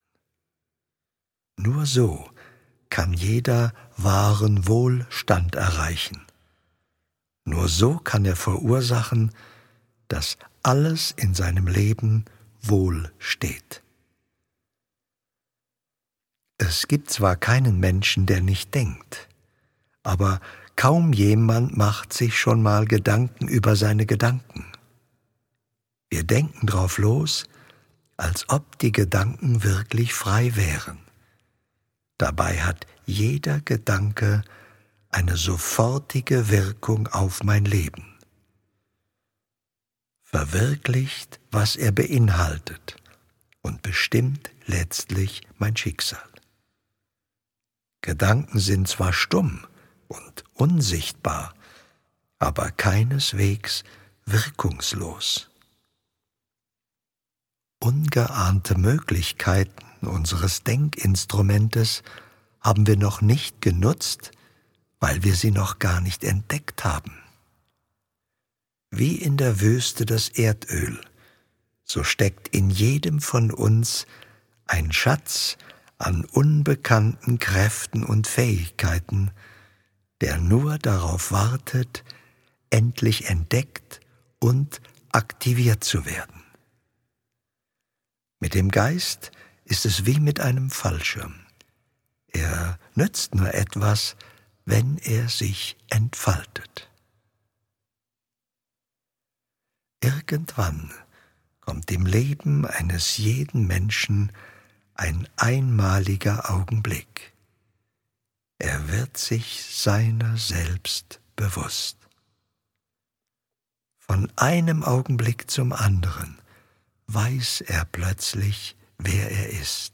Alltagsrezepte: Wie man sein Potential entfaltet - Hörbuch